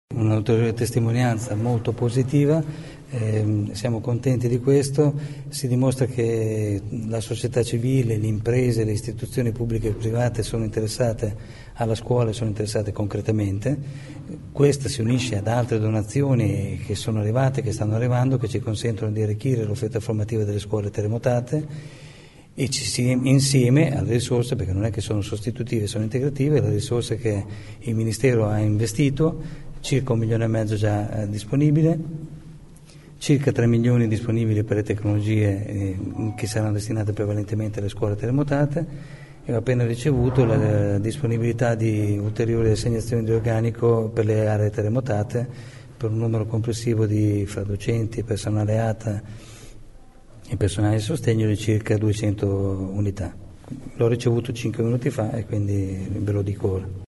Ascolta il dirigente dell’Usr Versari